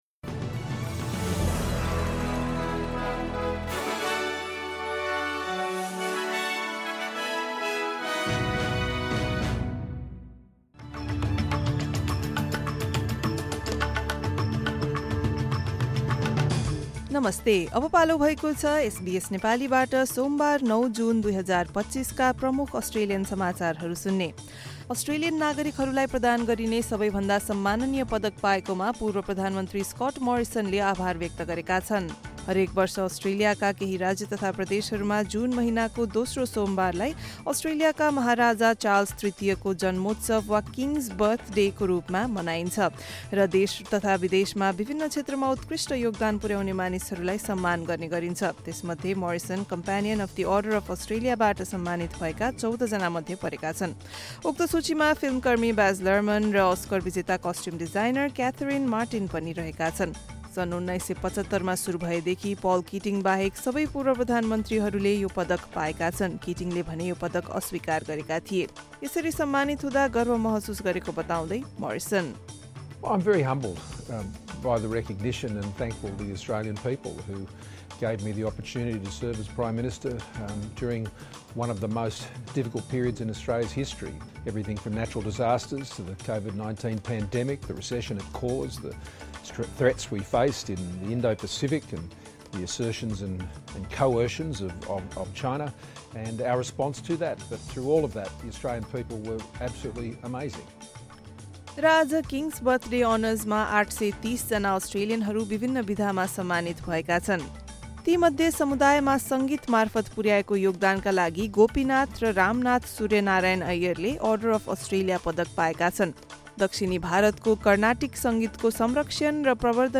एसबीएस नेपाली प्रमुख अस्ट्रेलियन समाचार: सोमवार, ९ जुन २०२५